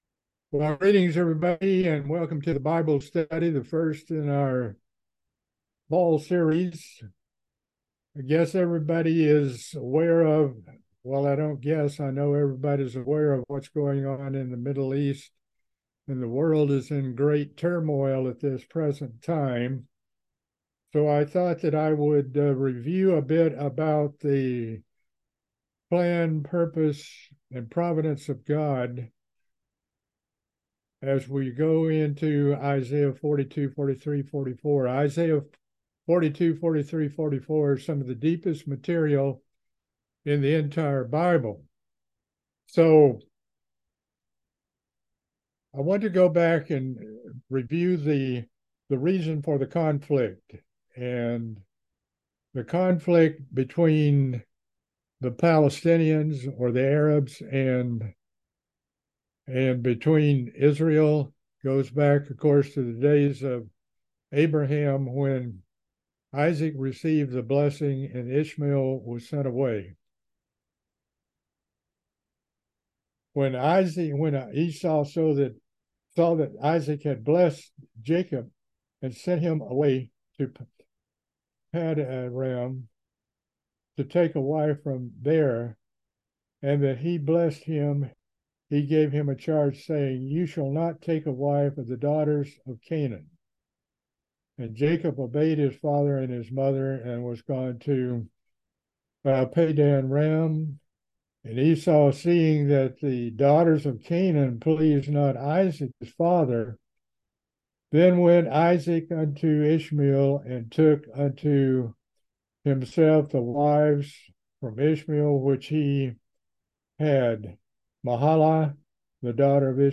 A continuing bible study in Isaiah. This time we will be starting in Chapter 42.